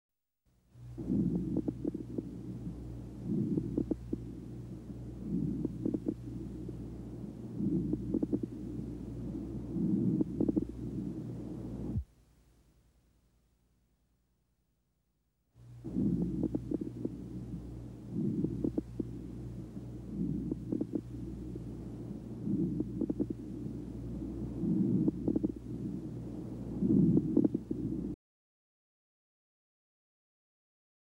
Coarse Crackles Right Lower Lobe